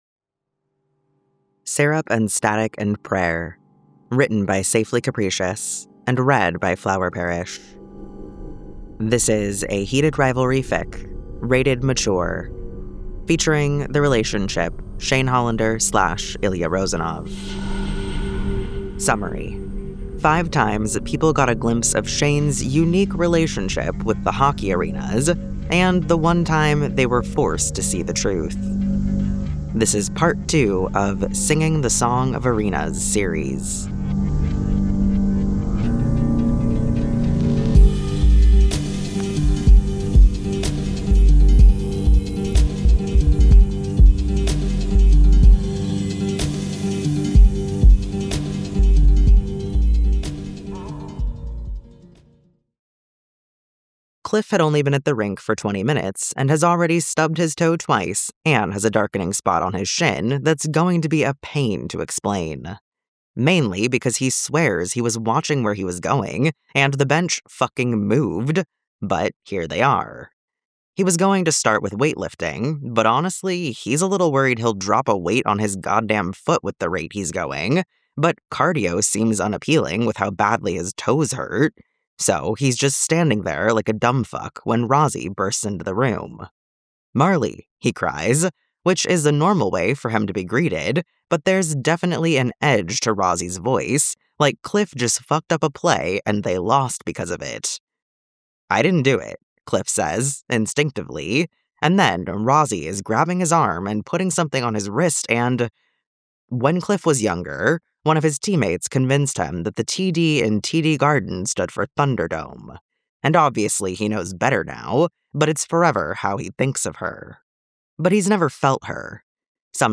SFX Version download mp3: here (r-click or press, and 'save link') [18 MB, 00:17:40]